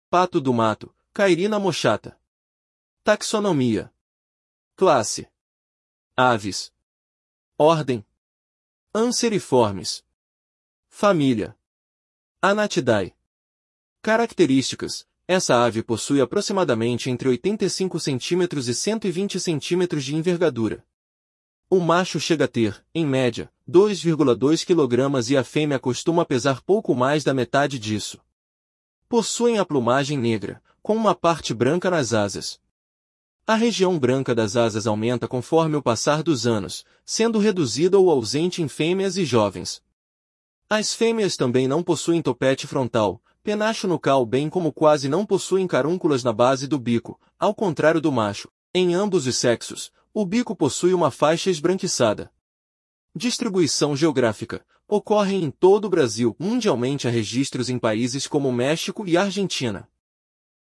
Pato-do-mato (Cairina moschata)
Sua vocalização é um bufar surdo que ocorre principalmente em disputas entre machos.